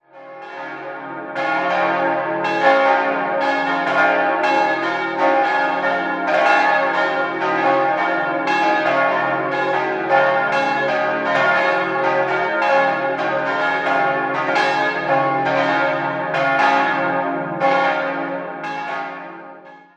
4-stimmiges Geläut: des'-es'-f'-as' Die drei größeren Glocken wurden 1946, die kleinste 1930 von Karl Hamm in Regensburg gegossen.